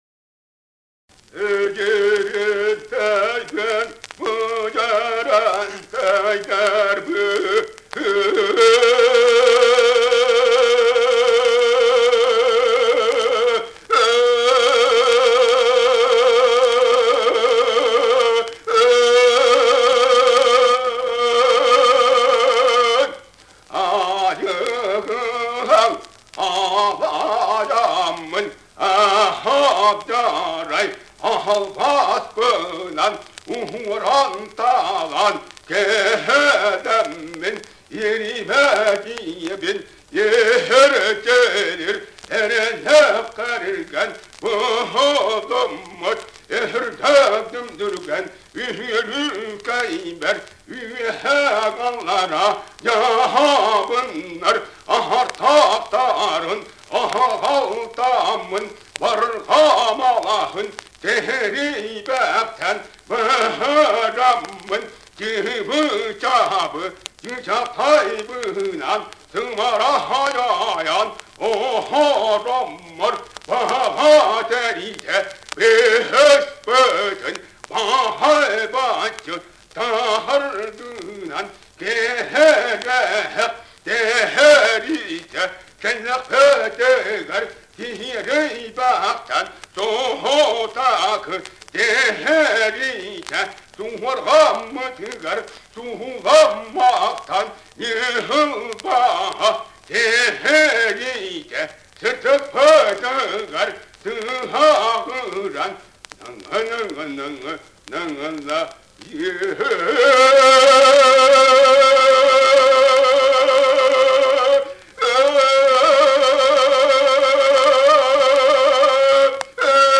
Якутский героический эпос "Кыыс Дэбилийэ"
Песня Богатыря Нижнего Мира из олонхо "Богатырь Кун Дьоhуолдьут".